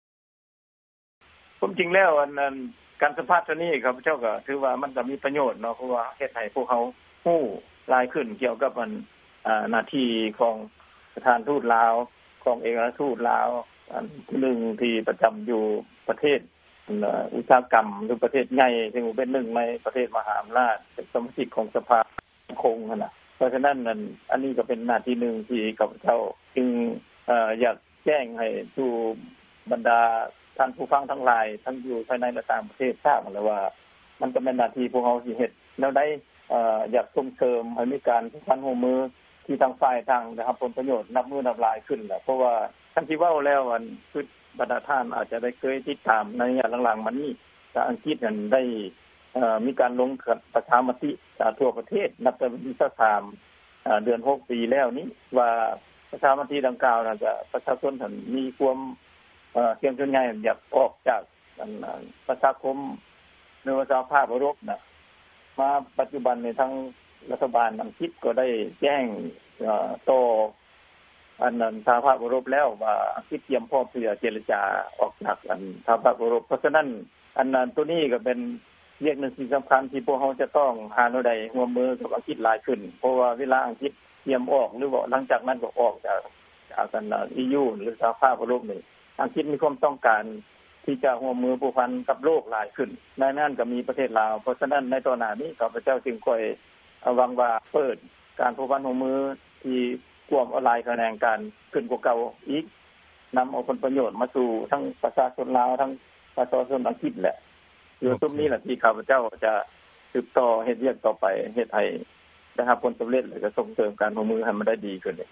ເຊີນຟັງການສຳພາດ ເອກອັກຄະລັດຖະທູດ ໄຊຍະການ ສີສຸວົງ